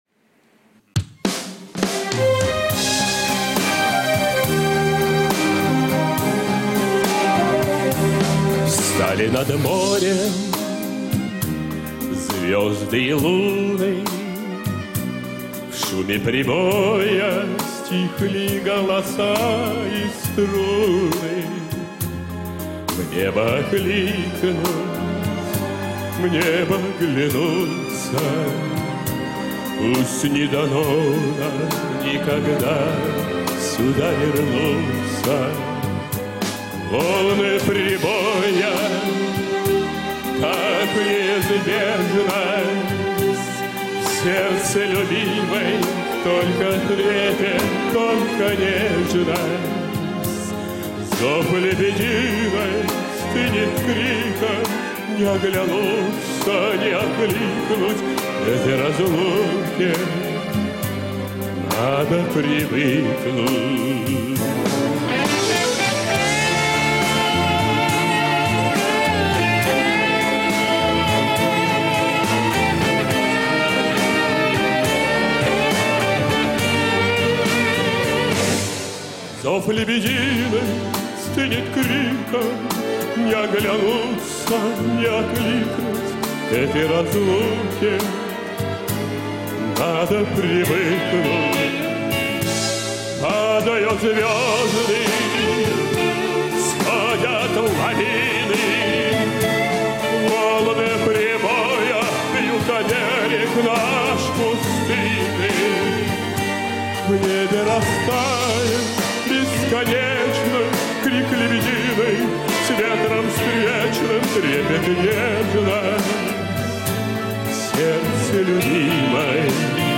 Звук я записала с ролика, лучше не получается.